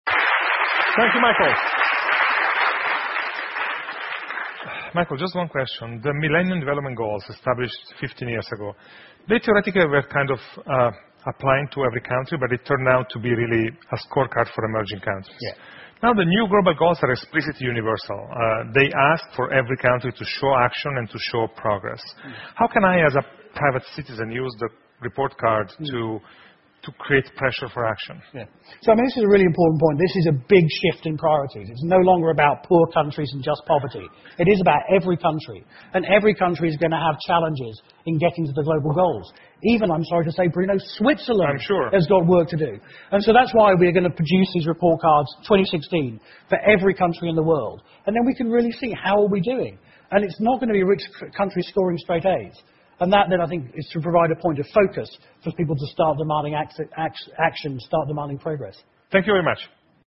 TED演讲:如何让世界到2030年时变得更好() 听力文件下载—在线英语听力室